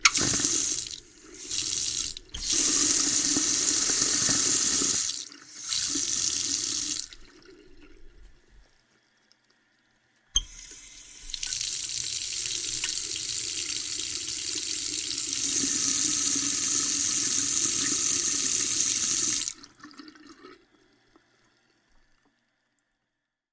随机的 " 浴室水槽跑水关闭各种
描述：卫生间水槽运行水关闭various.flac